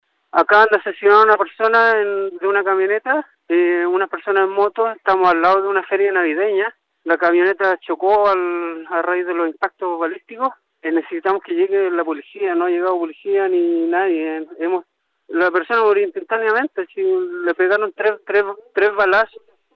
Un auditor llamó a La Radio y alertó el hallazgo de una persona baleada al interior de una camioneta en calle Simón Bolívar.